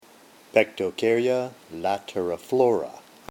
Pronunciation/Pronunciación:
Pec-to-cár-y-a la-te-ri-flò-ra